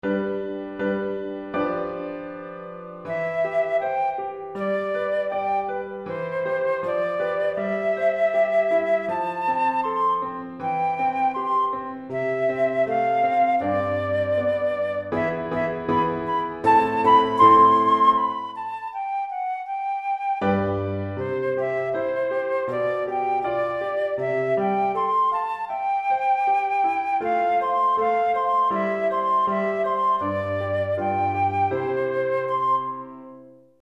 Flûte Traversière et Piano